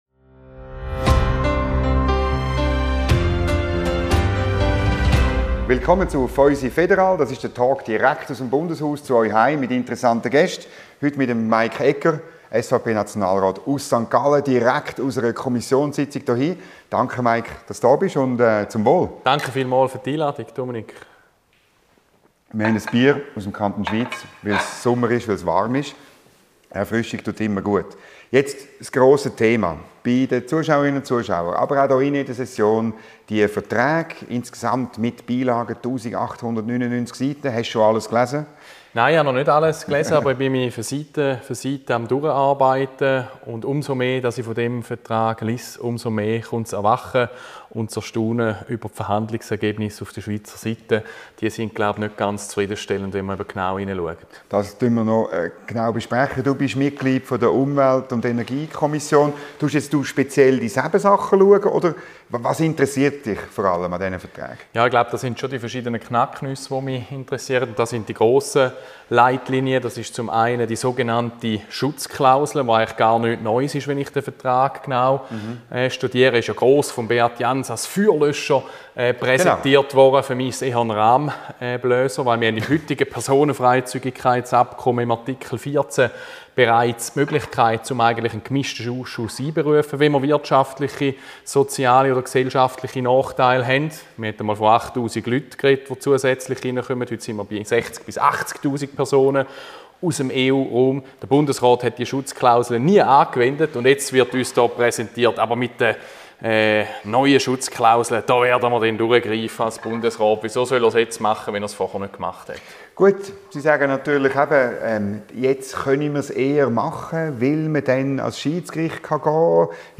im Gespräch mit dem St. Galler SVP-Nationalrat Mike Egger.